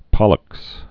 (pŏləks)